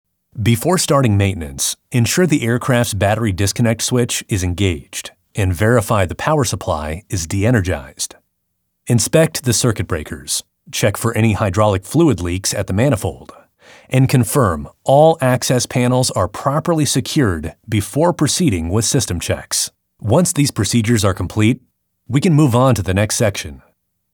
NARRATION
A no-nonsense, practical guide for machinery and industrial safety training, ensuring learners understand each critical step with a focus on hands-on processes and attention to detail.